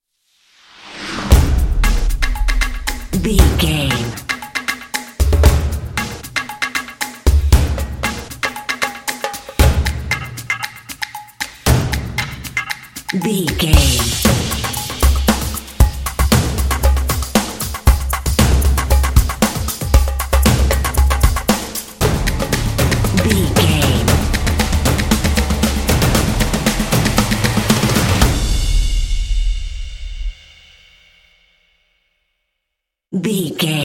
Epic / Action
Atonal
groovy
intense
driving
energetic
drumline